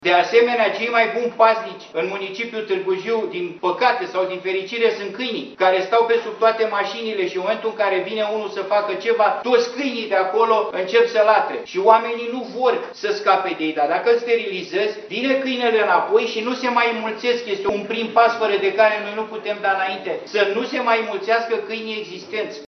Ei s-au întrunit într-o ședință de urgență pentru a găsi soluții, astfel încât să nu se mai repete incidente similare.
Cea mai controversată soluţie, deşi a fost îmbrăţişată de majoritatea consilierilor, a venit din partea reprezentantului PSD, Cristian Toader Pasti.
consilier-PSD-Targu-Jiu.mp3